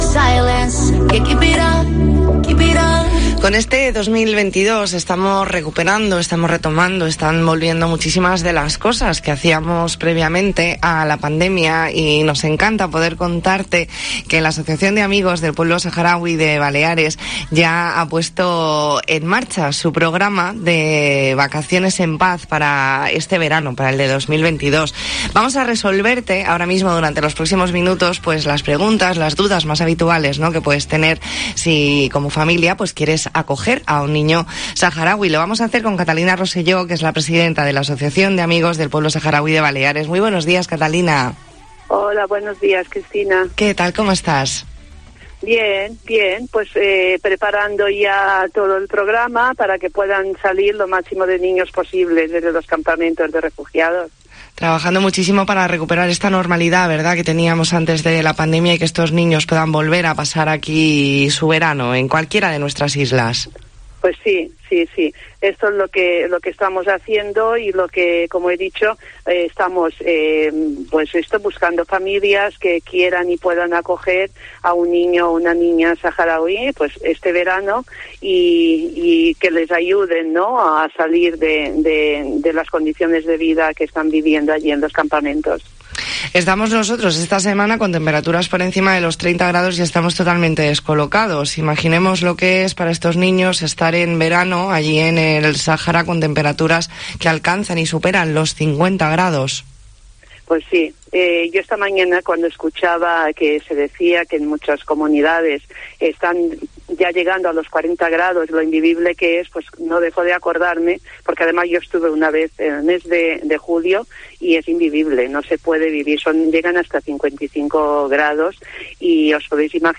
E ntrevista en La Mañana en COPE Más Mallorca, jueves 19 de mayo de 2022.